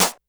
Snare_10.wav